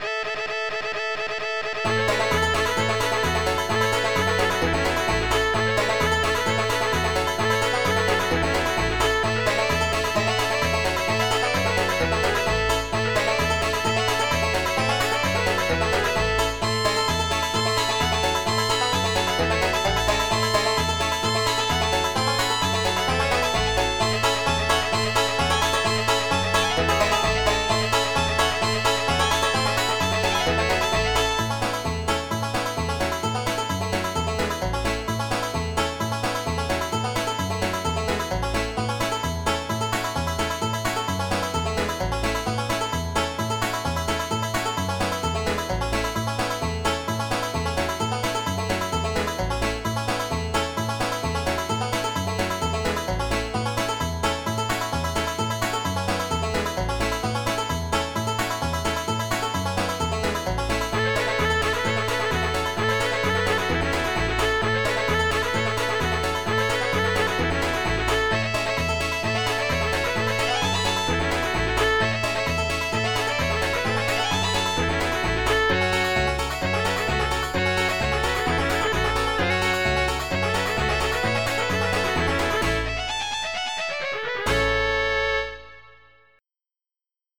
Oldies
MIDI Music File
Type General MIDI